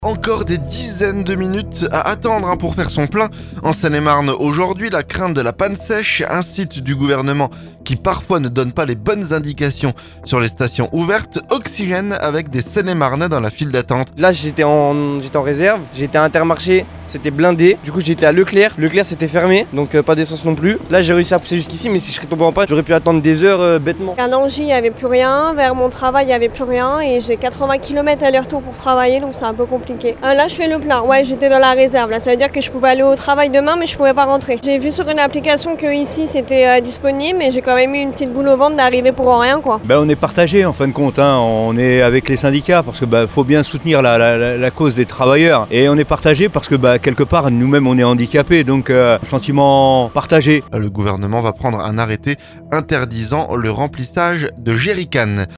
Oxygène avec des Seine-et-Marnais dans la file d'attente.